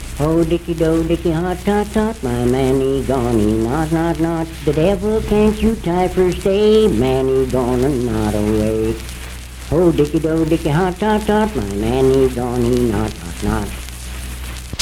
Unaccompanied vocal music
Verse-refrain 1(6). Performed in Sandyville, Jackson County, WV.
Voice (sung)